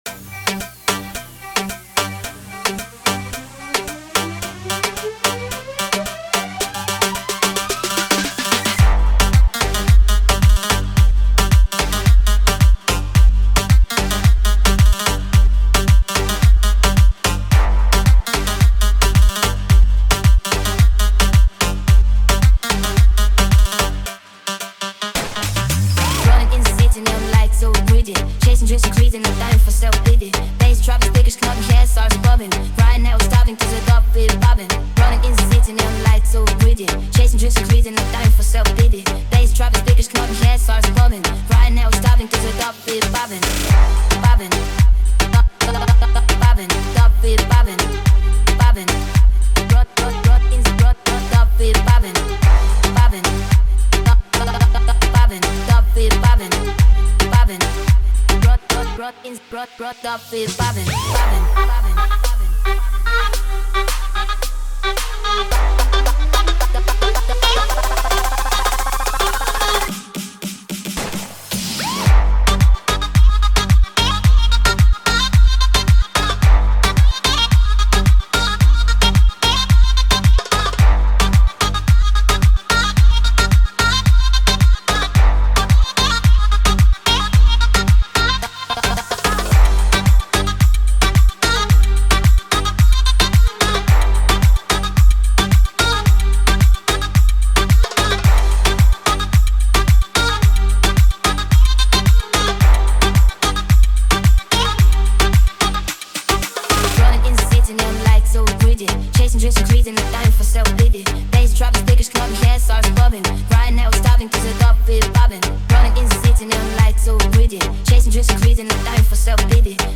دانلود ریمیکس جدید کلاب ۲۰۲۴